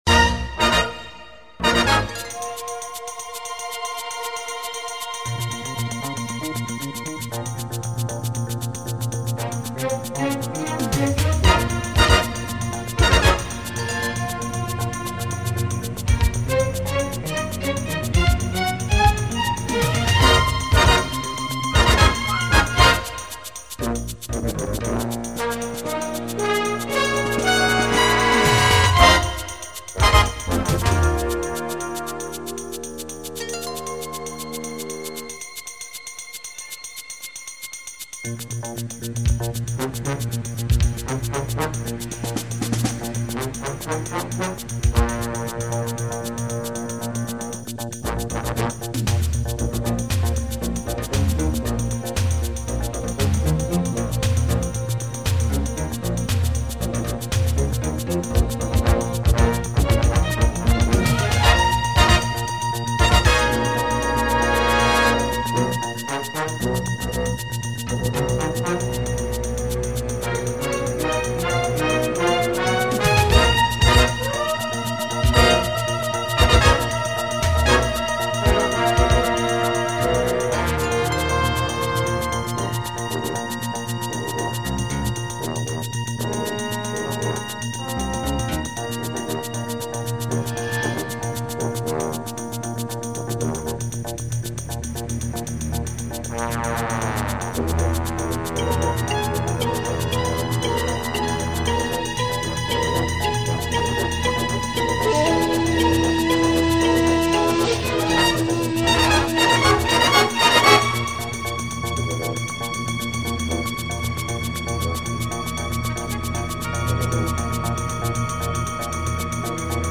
Enfin, plutôt clairon et trompette.